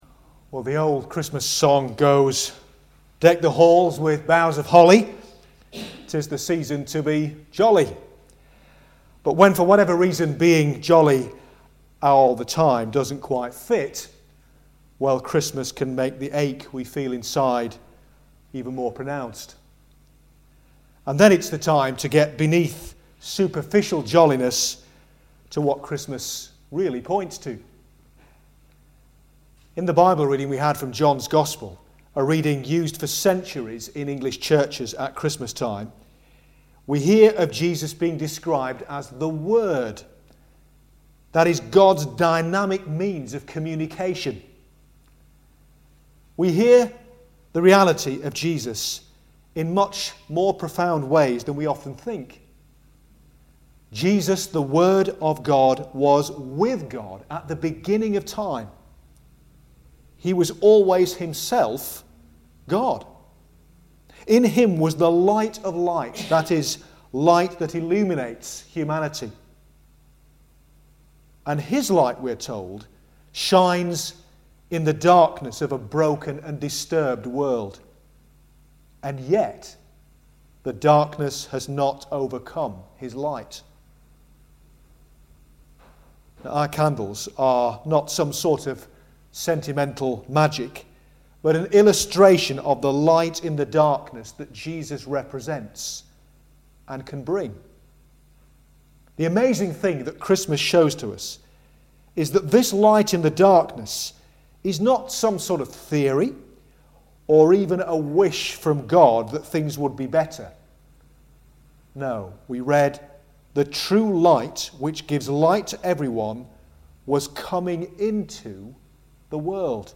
The “Longest Night” Service – for those for whom Christmas can be a difficult season, faced with a heavy heart.
2016-Longest-Night-Service.mp3